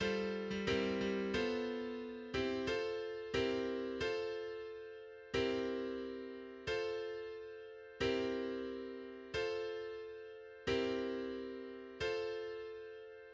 \header { tagline = "" } \layout { indent = #0 } akkorde = \chordmode { \germanChords % \partial 4 f4 c:7 f4. c8 f4 c:7 f2 c:7 f c:7 f c:7 f } melodie = \relative c' { \time 4/4 \tempo 4 = 90 \key b \major \autoBeamOff % \partial 4 g8. g16 g8 g b4..